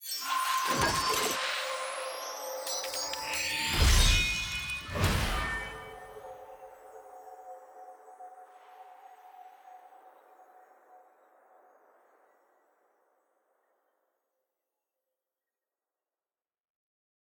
sfx-clash-capsule-tier-2-ante-1.ogg